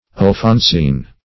alphonsine - definition of alphonsine - synonyms, pronunciation, spelling from Free Dictionary
Search Result for " alphonsine" : The Collaborative International Dictionary of English v.0.48: Alphonsine \Al*phon"sine\ ([a^]l*f[o^]n"s[i^]n), a. Of or relating to Alphonso X., the Wise, King of Castile (1252-1284).